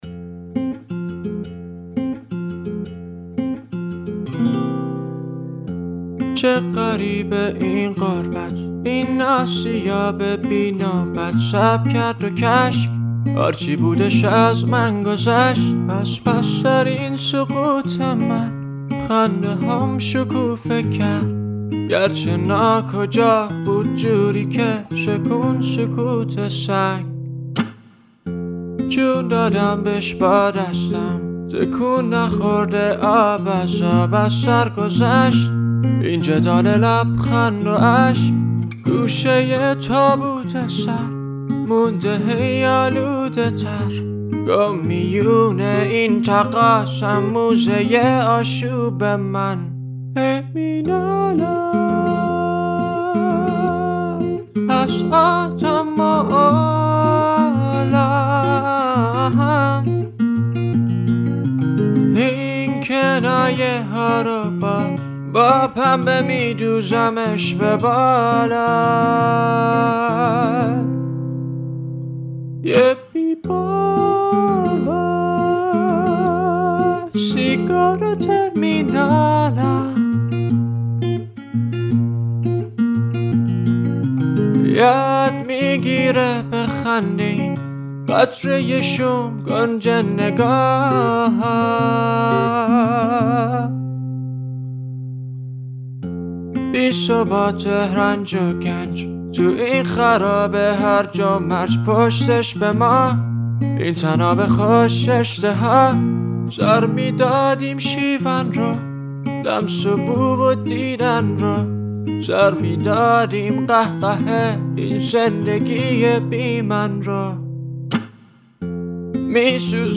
پاپ ایندی Indie Music